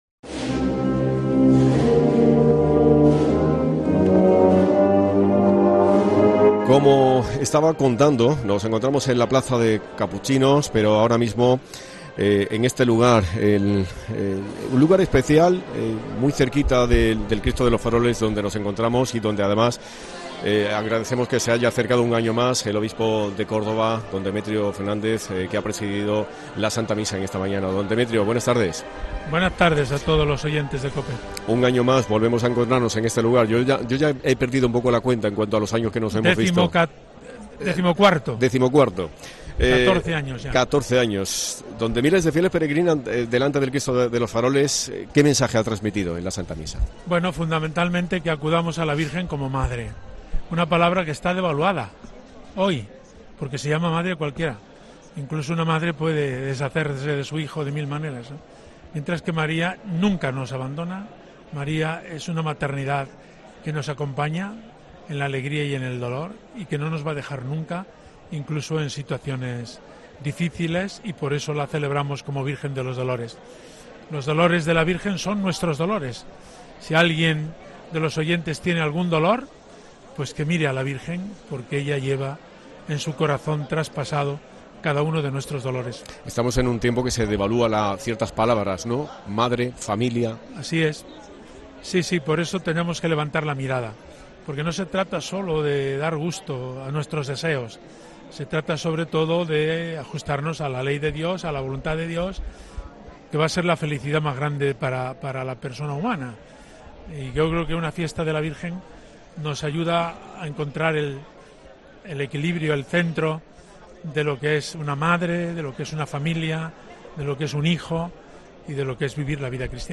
Escucha al obispo de Córdoba, Demetrio Fernández